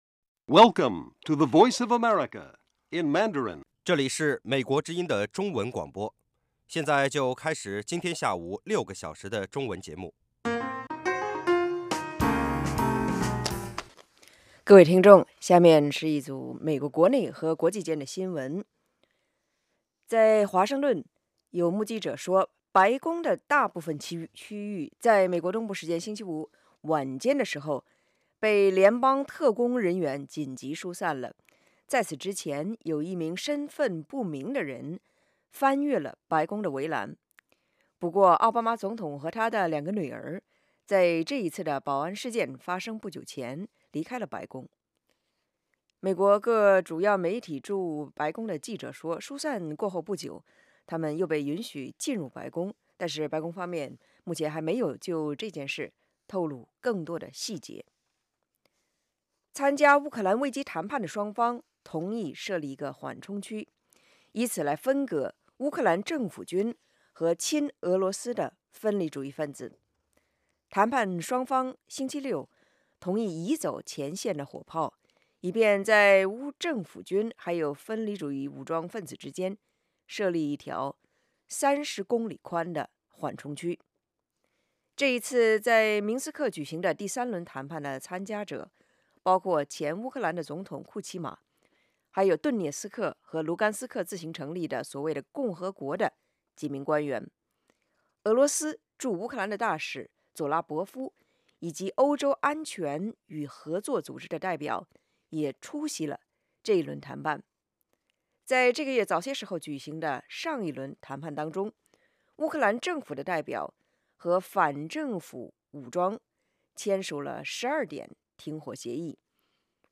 晚5-6点广播节目